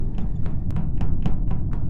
Among Us Steps Sound Effect Free Download
Among Us Steps